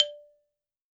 52-prc04-bala-d3.wav